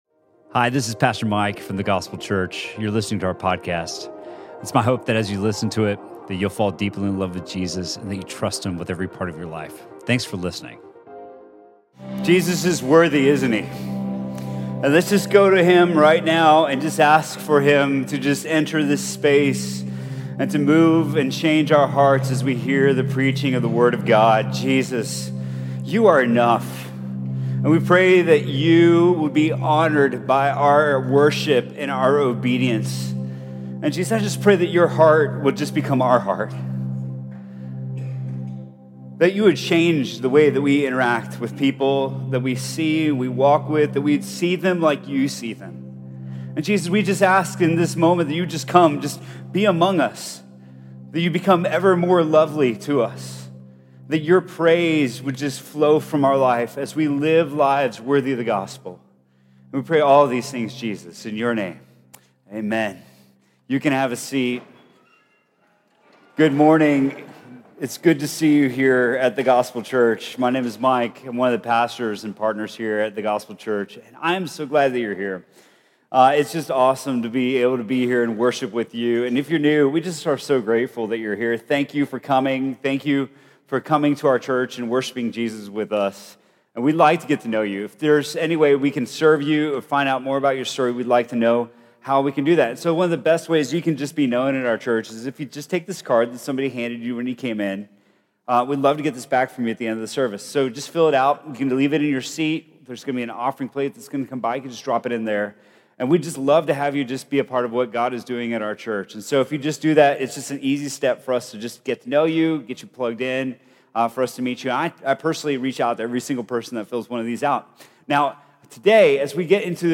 Sermon from The Gospel Church on October 7th, 2018.